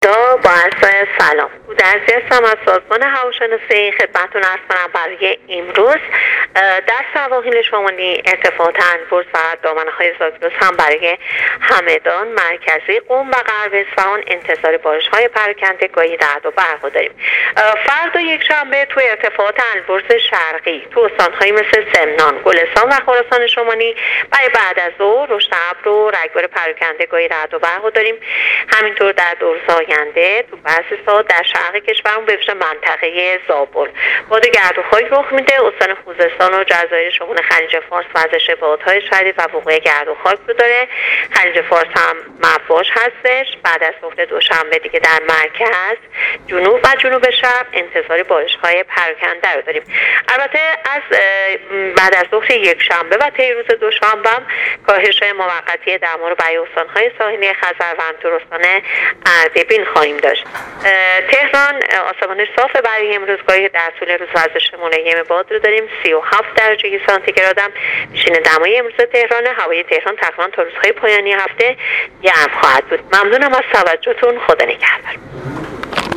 کارشناس سازمان هواشناسی کشور در گفت‌وگو با رادیو اینترنتی وزارت راه و شهرسازی، آخرین وضعیت آب و هوای کشور را تشریح کرد.
گزارش رادیو اینترنتی از آخرین وضعیت آب و هوای ۱۰ خرداد: